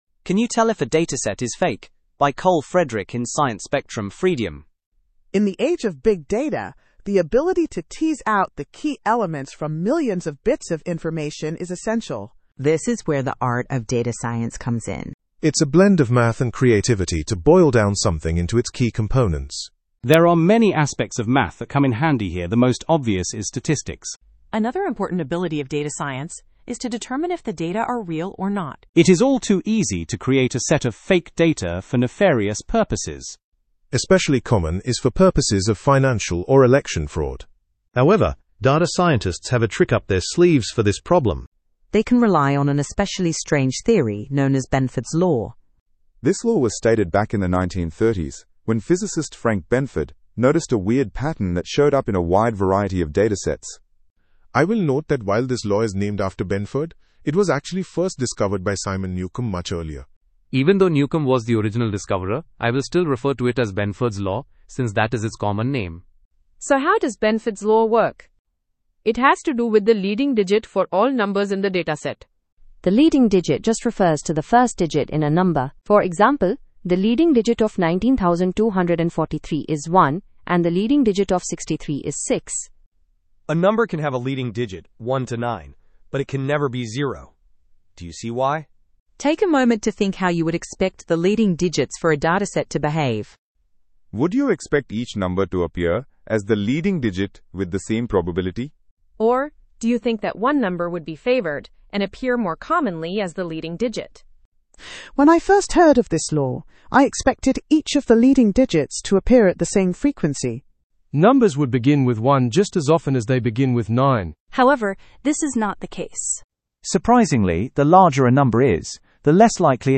Sound quality of the google cloud TTS API
Its quality is better than LingQ’s ones.